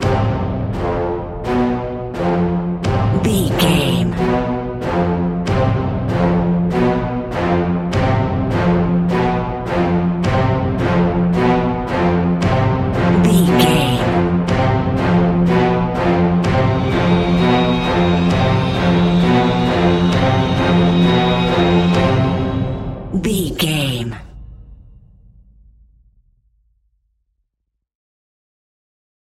In-crescendo
Lydian
tension
ominous
dark
suspense
haunting
eerie
brass
strings
drums
percussion
synths
atmospheres